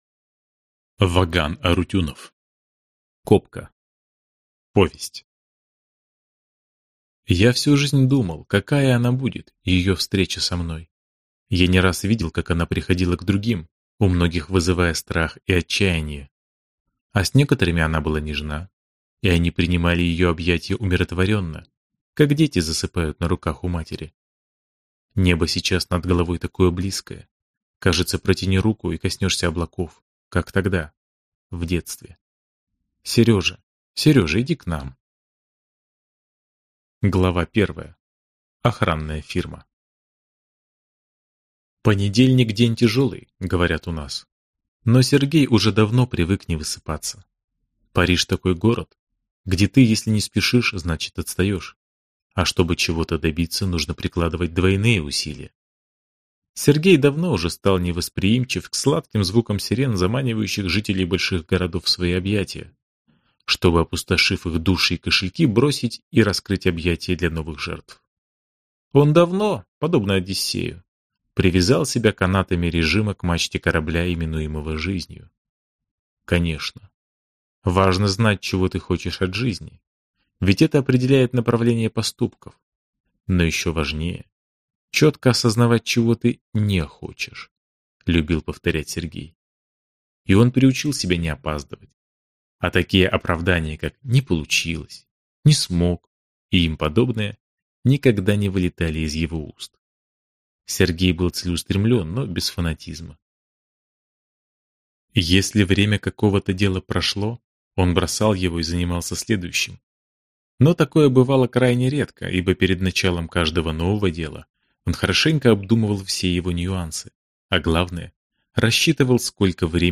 Аудиокнига Копка | Библиотека аудиокниг